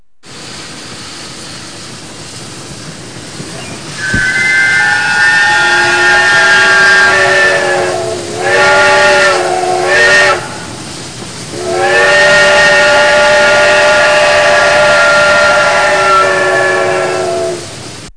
train3.mp3